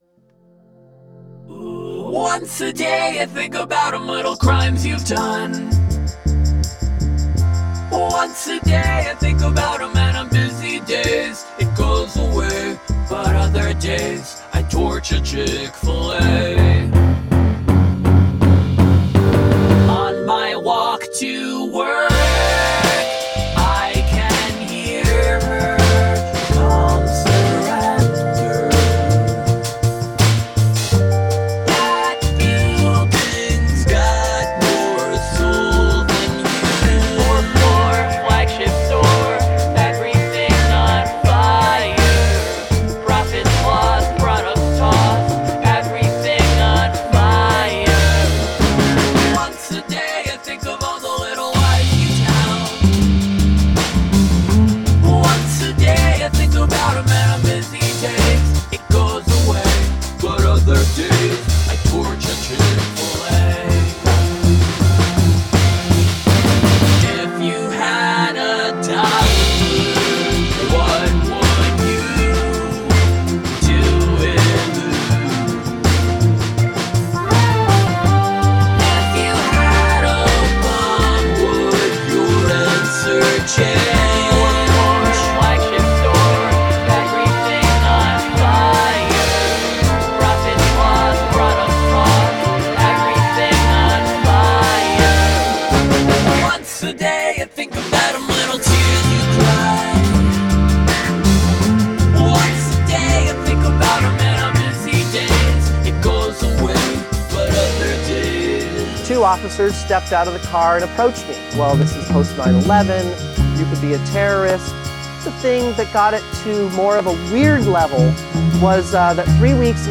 post-mcluhanist chamber punk for the cultural epidemic